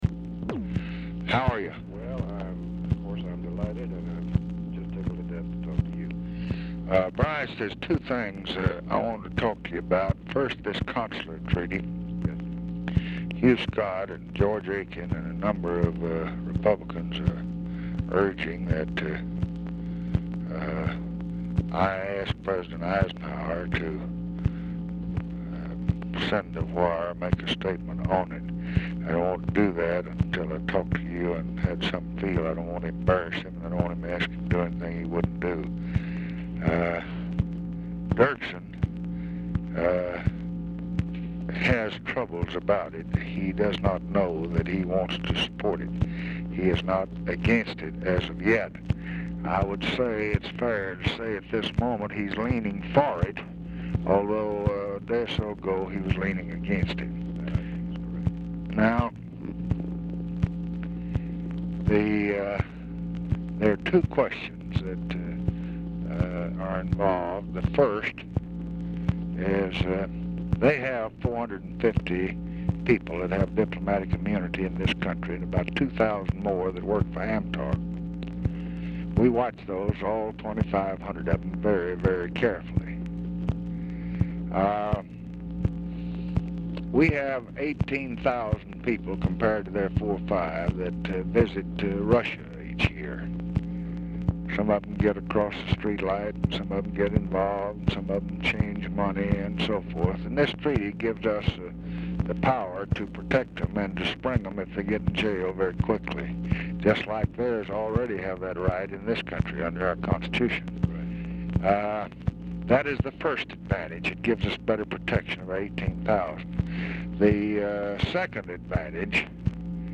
Telephone conversation # 11503, sound recording, LBJ and BRYCE HARLOW, 2/2/1967, 10:37AM | Discover LBJ
Format Dictation belt
Location Of Speaker 1 Oval Office or unknown location
Specific Item Type Telephone conversation